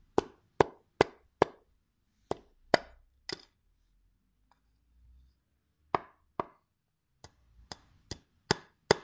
榔头
描述：将钉子钉入一块木头
Tag: 锤击 建筑 敲打 工作 OWI 建筑